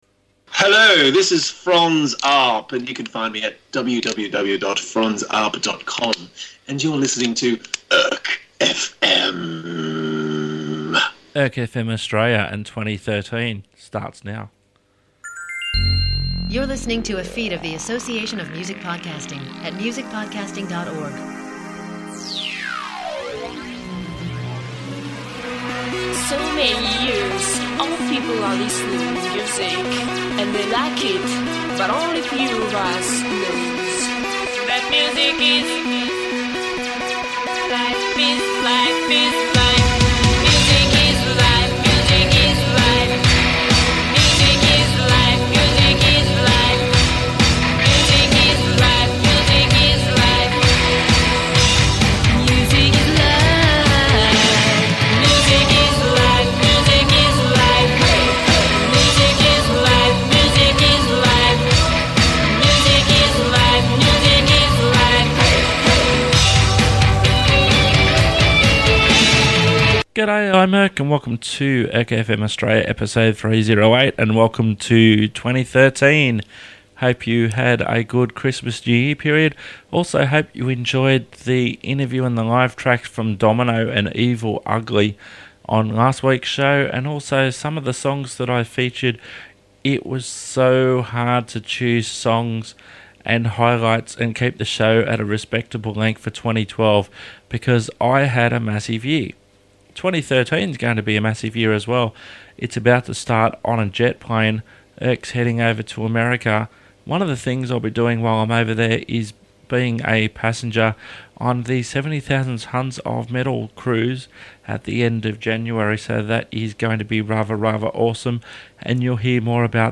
But don't worry - even if you aren't a metalhead, you will enjoy this range of tunes. All music supplied directly by the artists.